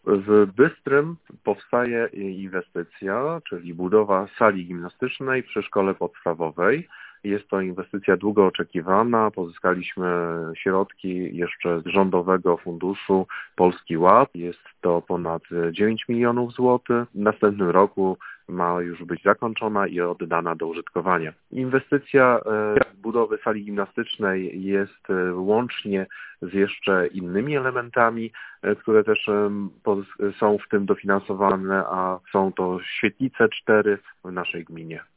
Mówił Radiu 5 Marek Jasudowicz, wójt gminy Giżycko.